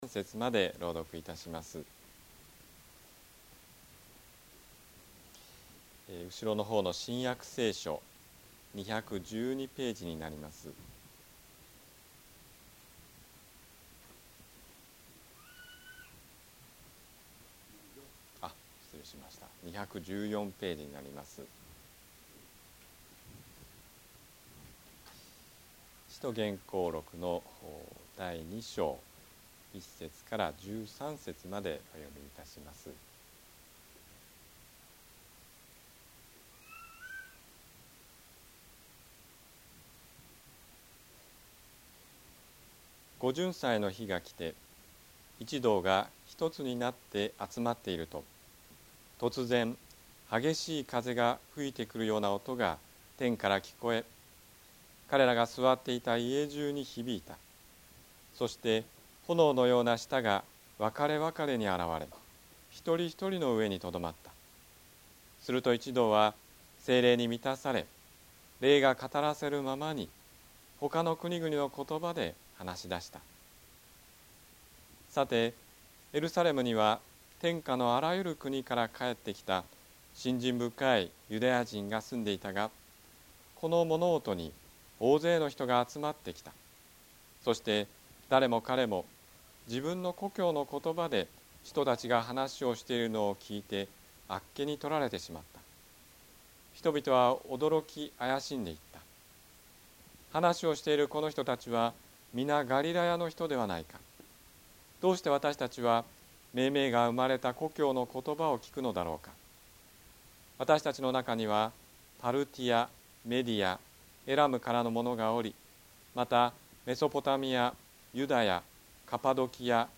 説教アーカイブ。
ホームページで礼拝説教の音声データを公開しています。
日曜 朝の礼拝